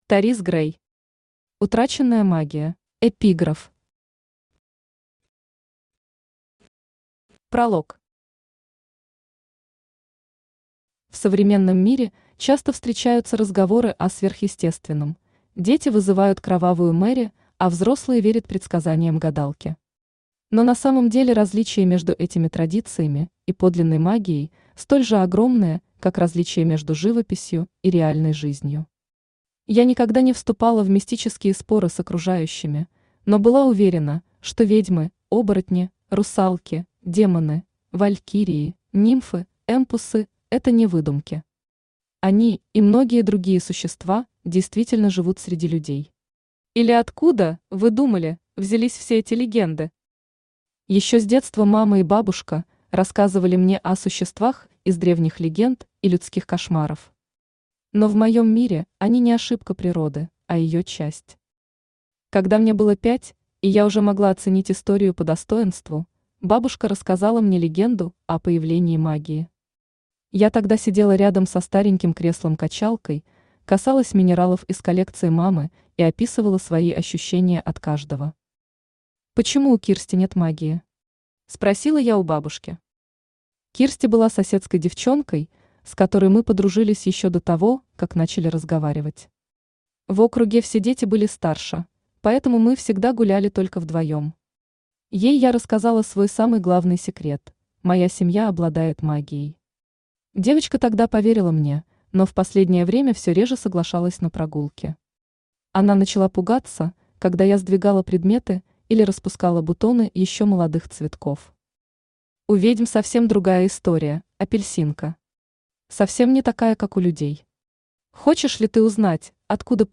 Аудиокнига Утраченная магия | Библиотека аудиокниг
Aудиокнига Утраченная магия Автор ТориZ Грей Читает аудиокнигу Авточтец ЛитРес.